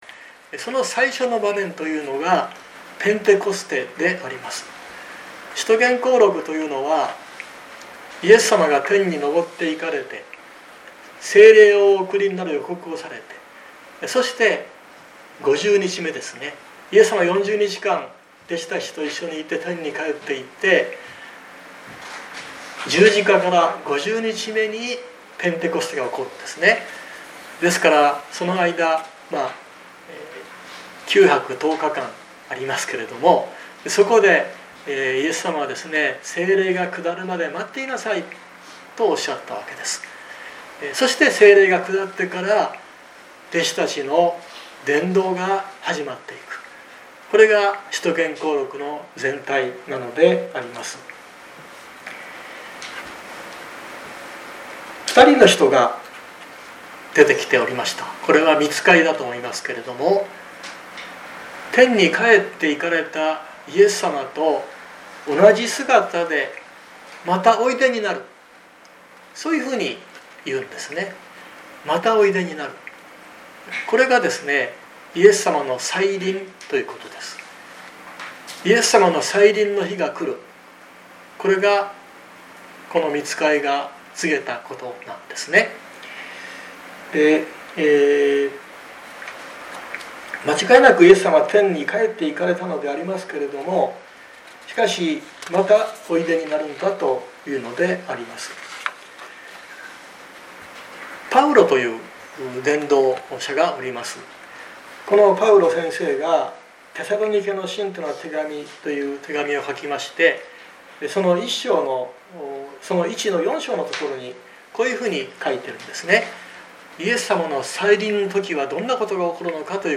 2024年06月30日朝の礼拝「地の果てに至るまで」熊本教会
説教アーカイブ。